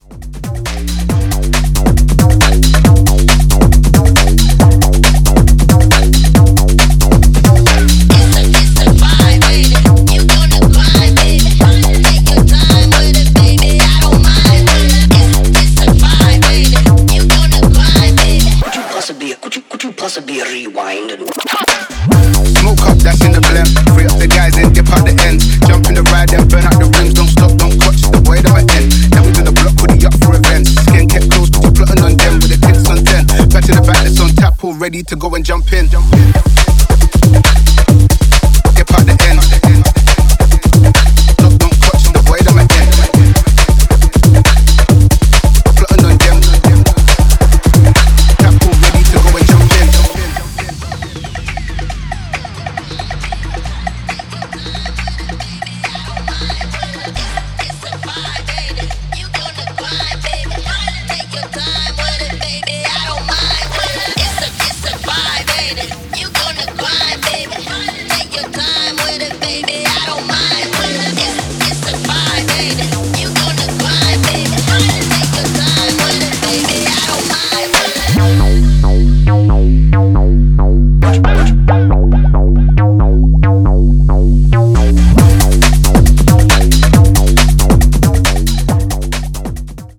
Styl: Breaks/Breakbeat Vyd�no